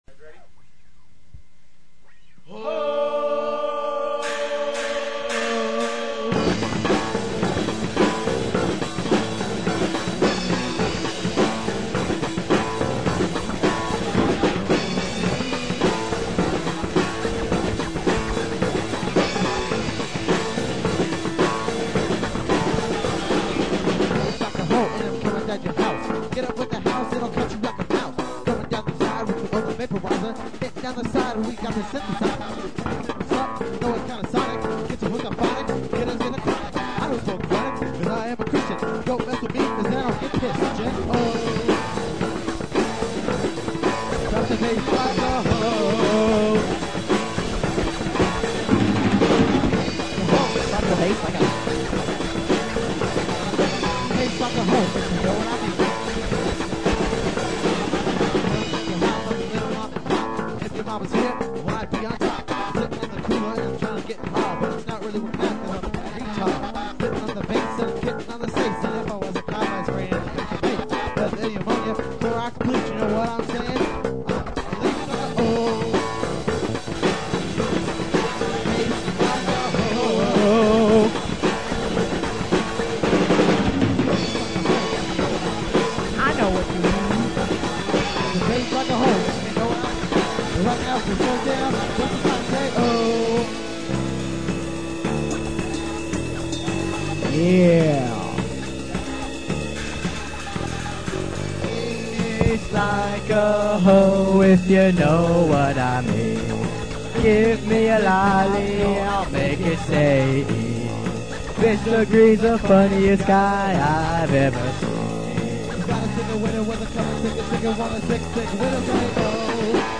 Guitar/Back-up Vocals
Turntables/ Back-up Vocals
Drums/Vocals
Bass/Vocals
Annoying Back-up Vocals